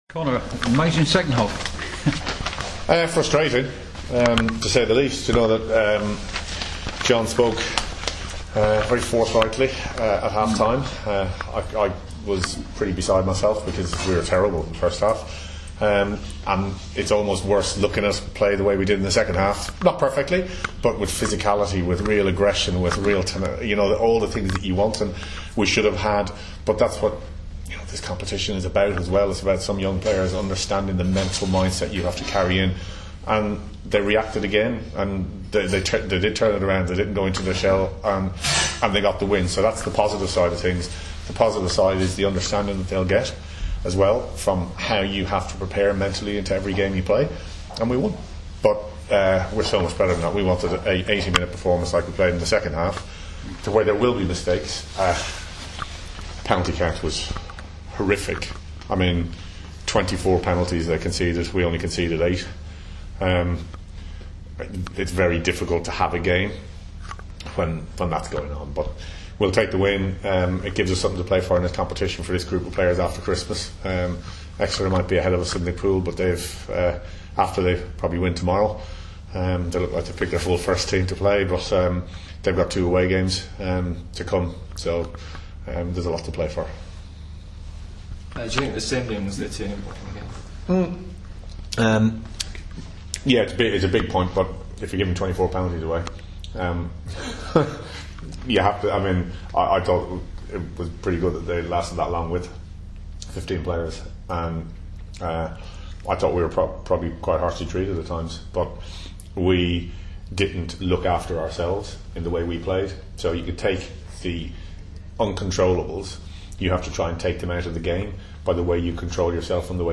Conor O'Shea, speaking after Harlequins win against Newport Gwent Dragons in the LV=Cup